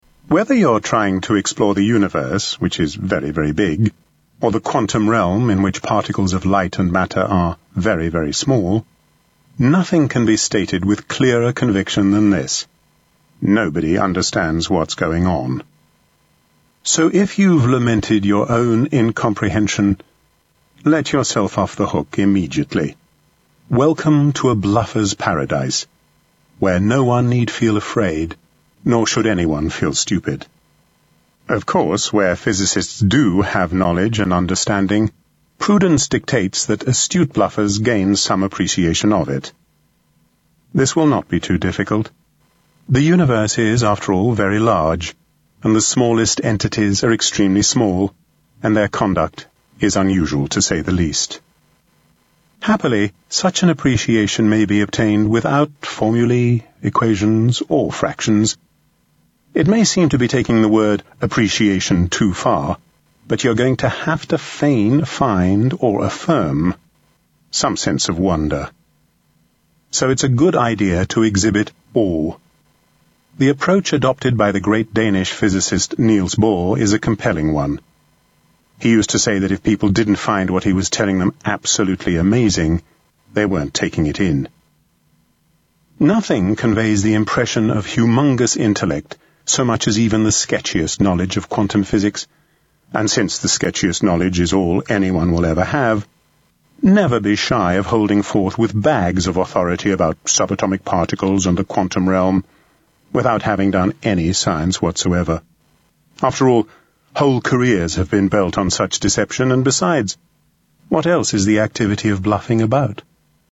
Tags: Quantum Physics Audio Books Quantum Physics Quantum Physics clips Physics Quantum Physics sound clips